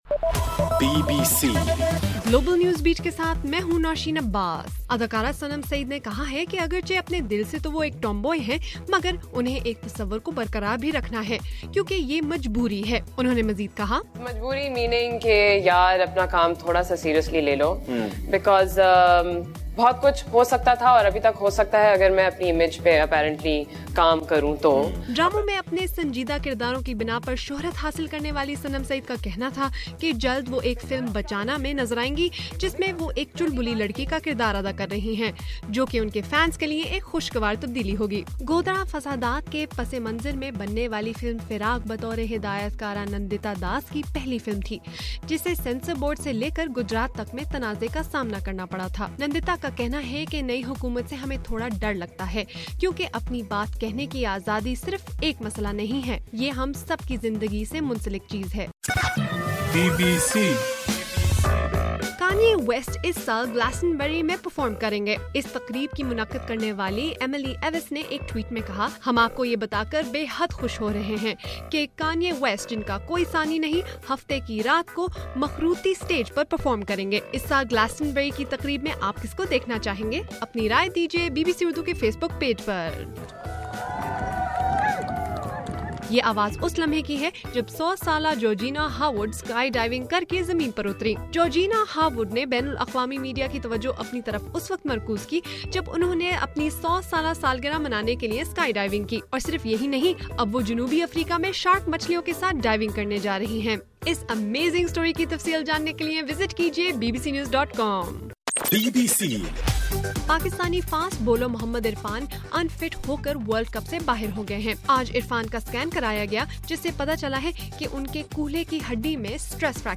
مارچ 17: رات 9 بجے کا گلوبل نیوز بیٹ بُلیٹن